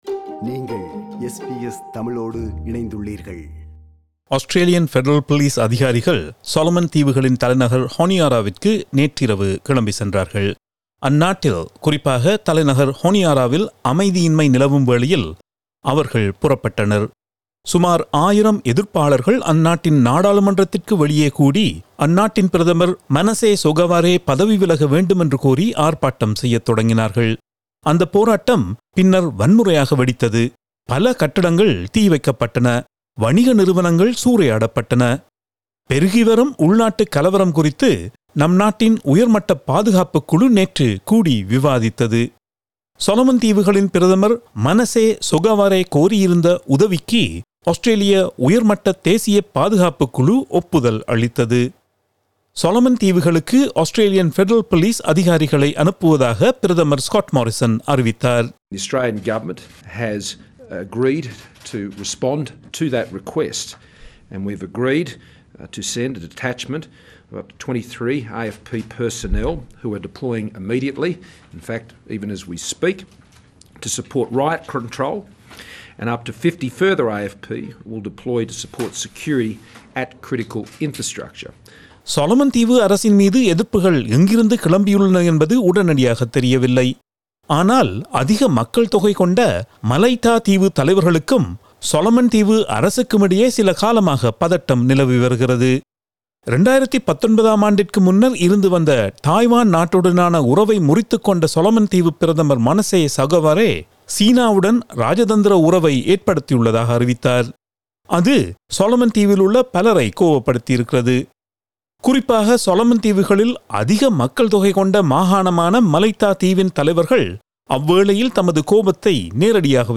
SBS Tamil